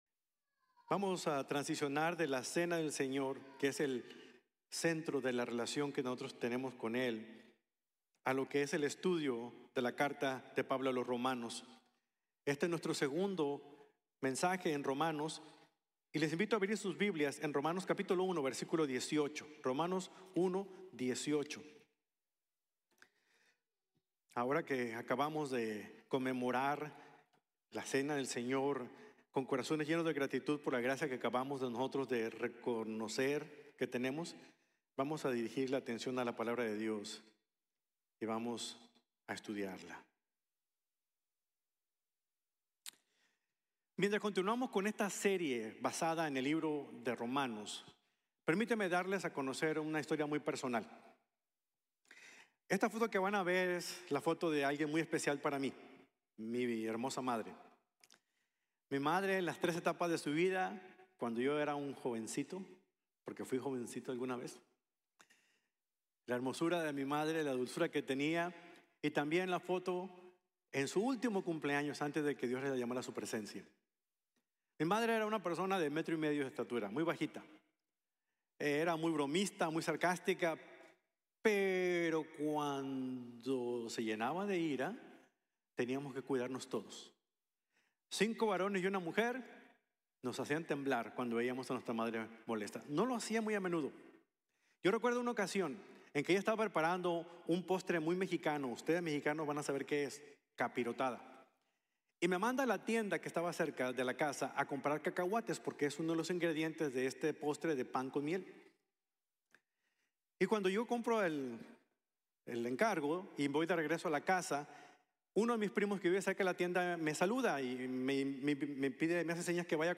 De la Ira a la Gracia | Sermon | Grace Bible Church